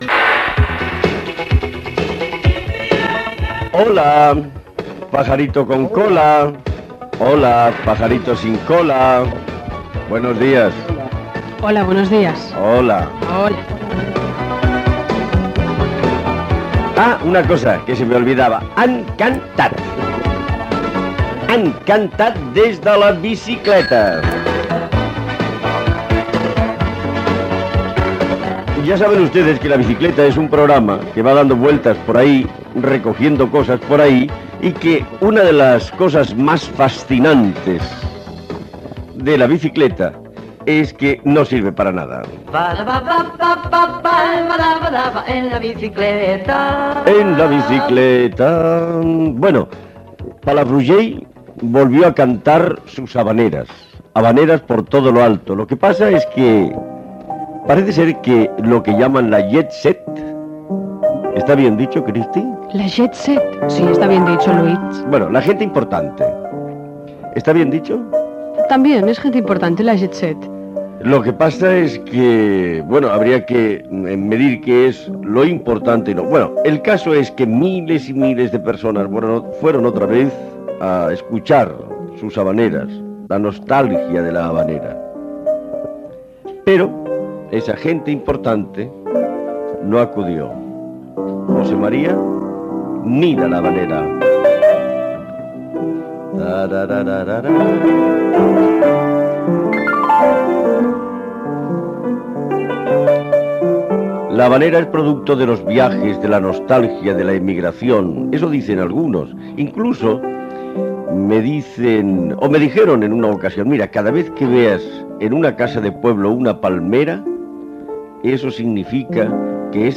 Salutació inicial, indicatiu del programa, la cantada d'havaneres de Calella de Palafrugell, el polític Roca Junyent, publicitat.
Entreteniment